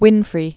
(wĭnfrē), Oprah Born 1954.